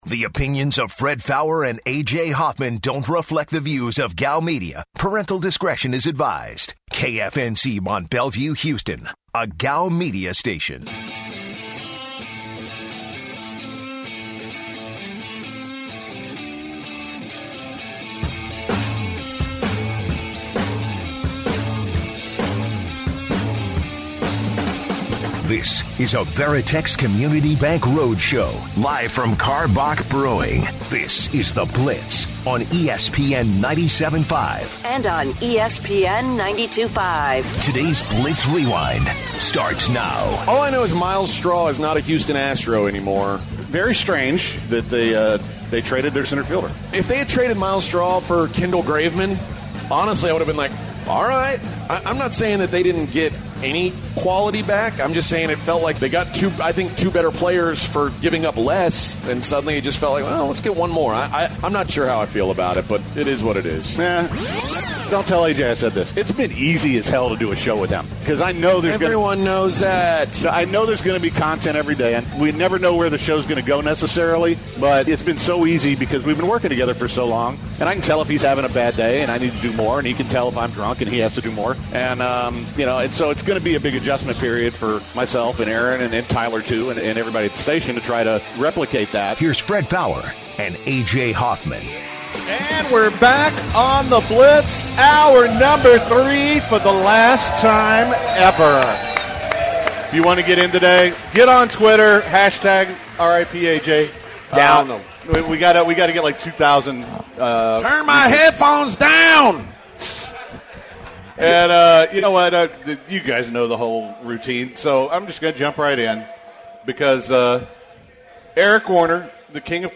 are live for one last time at Karbach Brewing for our ESPN 97.5 Summer Circuit.